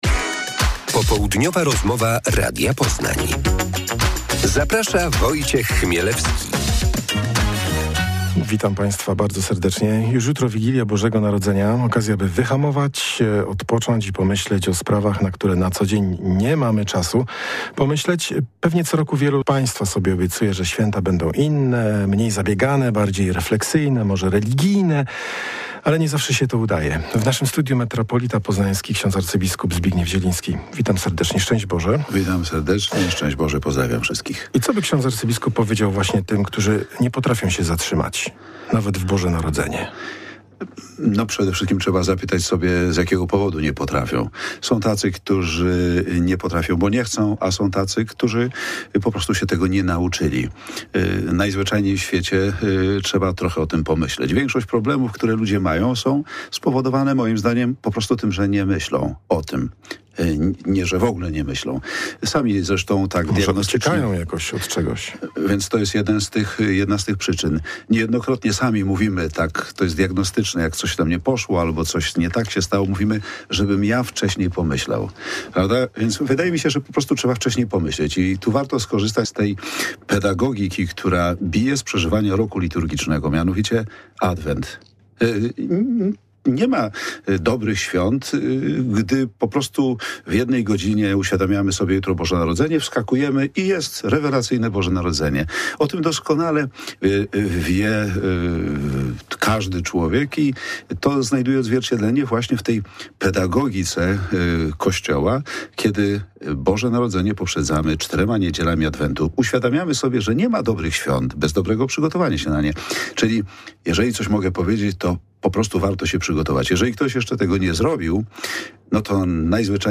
Popołudniowa rozmowa Radia Poznań – abp Zbigniew Zieliński
c3cp7xeyuiez144_popoludniowa-rozmowa-radia-poznan-abp-zbigniew-zielinski.mp3